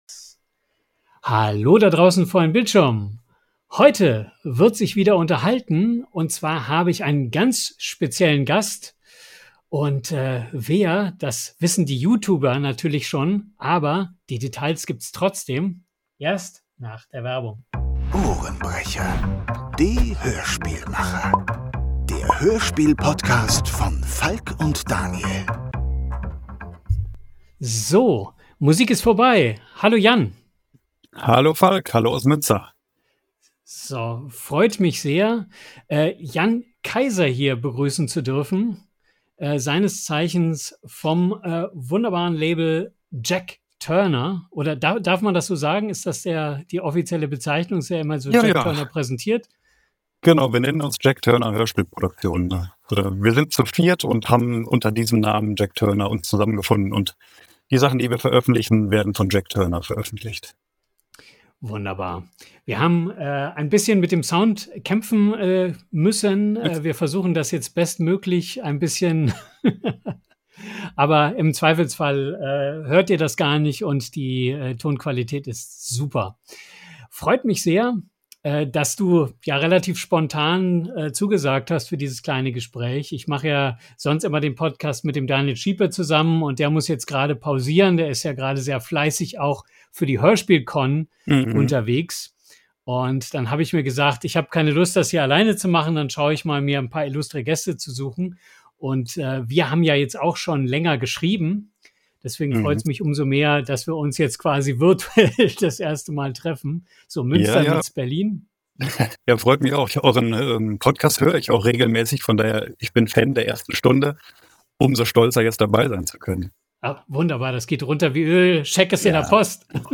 Episode 52: Horror, Mystery & Sounddesigns – Im Gespräch